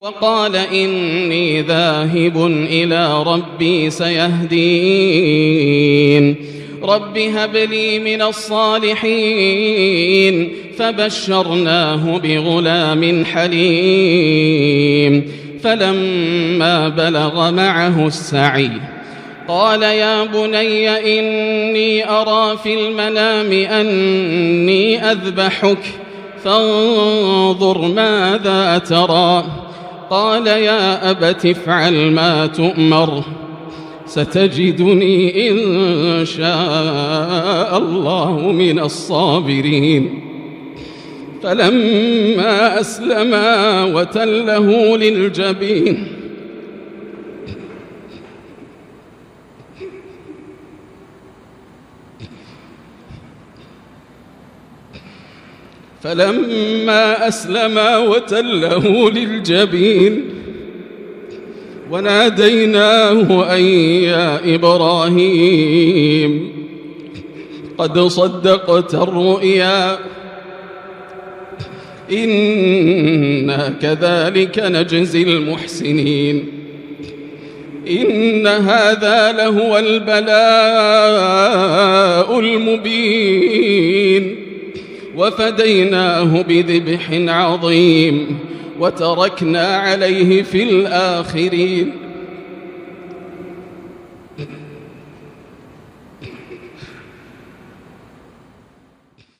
آيات قصة الذبيح التي أبكت الشيخ ياسر الدوسري بكاءً مريراً ~ مقطع تاريخي لا يُنسى > مقتطفات من روائع التلاوات > مزامير الفرقان > المزيد - تلاوات الحرمين